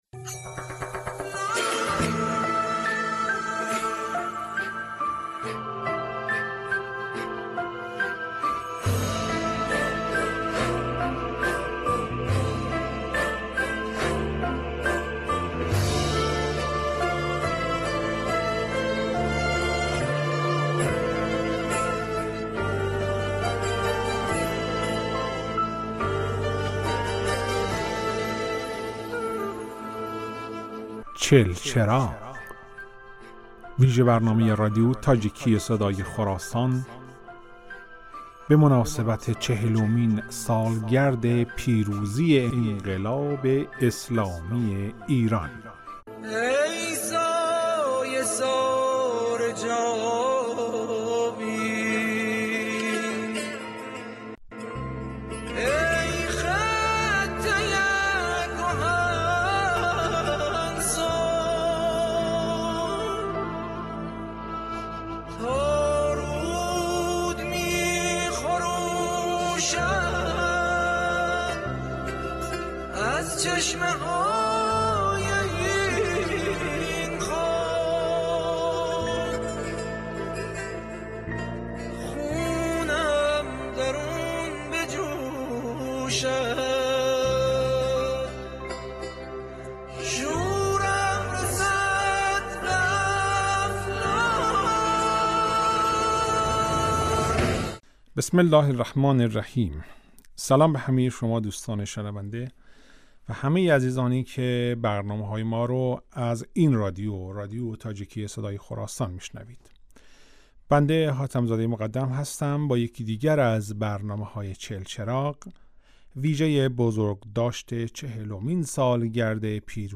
چهلچراغ ویژه برنامه ای است که به مناسبت چهلمین سالگرد پیروزی انقلاب اسلامی ایران در رادیو تاجیکی صدای خراسان تهیه شده است.